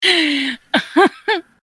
Tamberlaugh1.mp3.ogg